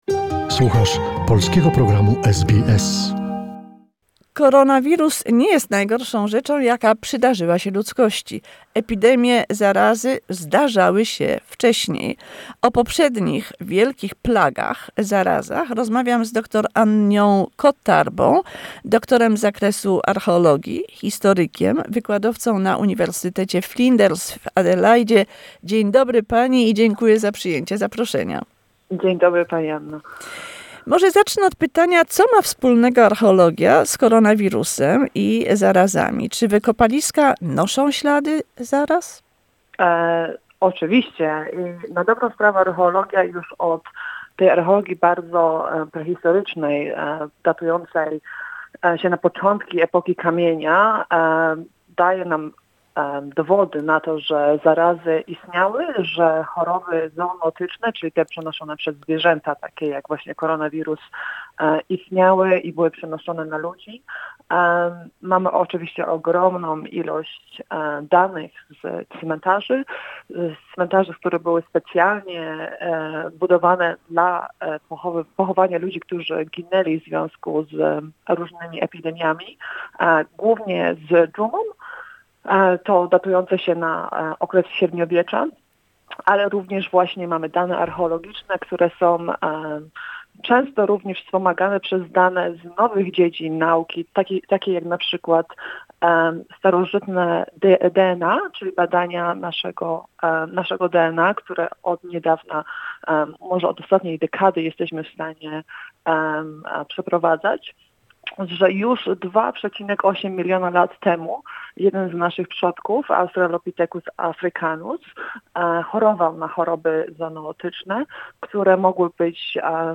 Where do the plagues come from? A conversation